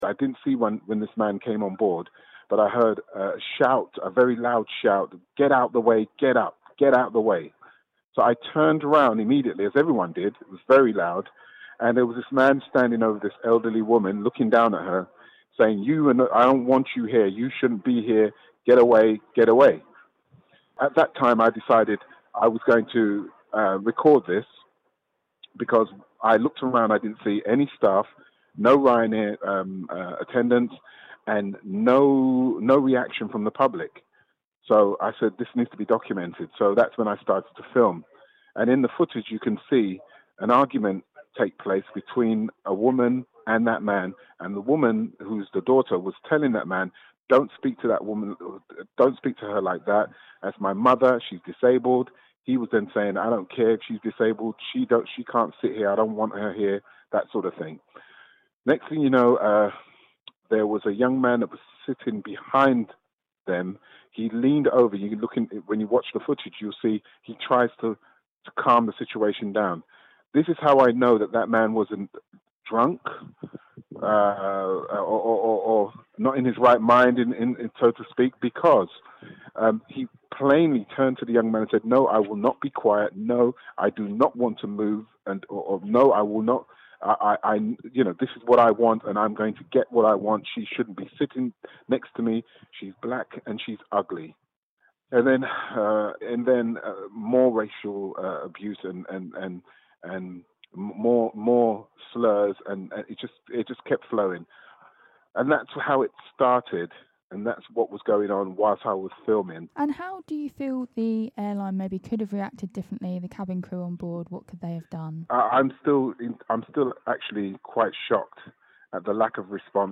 A man from Rochester has told kmfm about the moment he filmed racist abuse being hurled at an elderly passenger on a Ryanair flight: